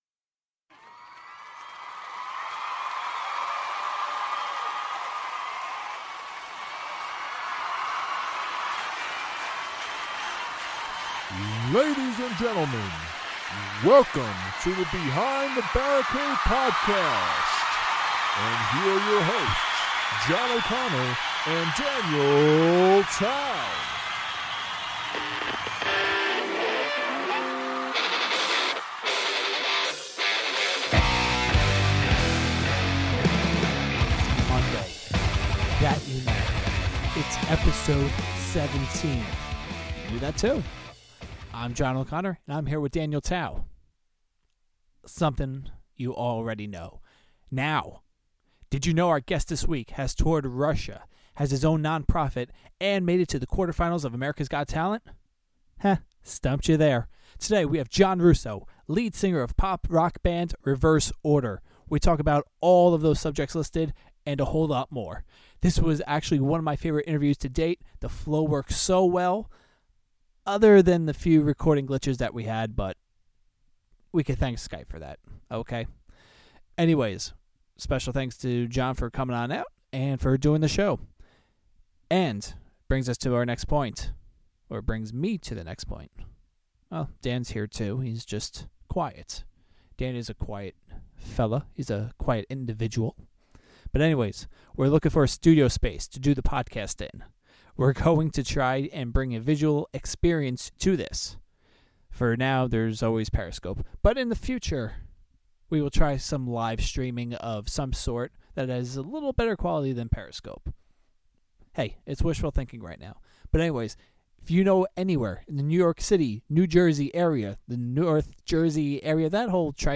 Pre-Interview Song: Erase Me Post-Interview Song: Call My Name